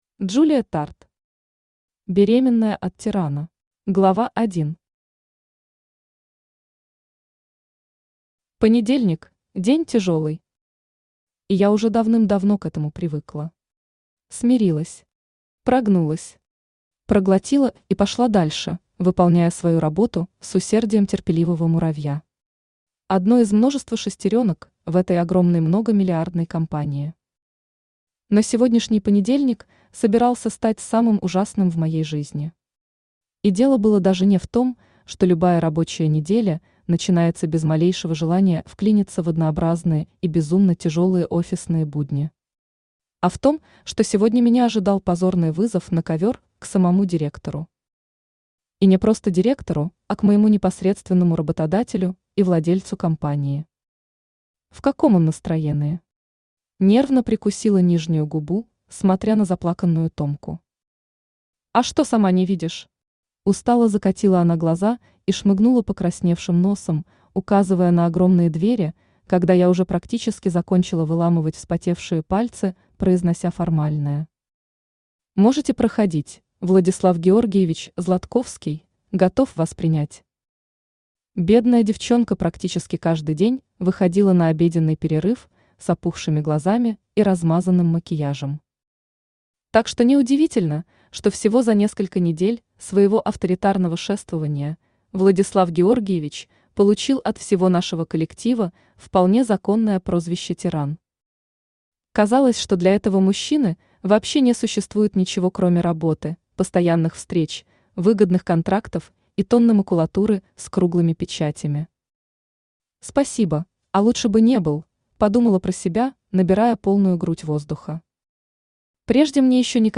Аудиокнига Беременная от тирана | Библиотека аудиокниг
Aудиокнига Беременная от тирана Автор Джулия Тард Читает аудиокнигу Авточтец ЛитРес.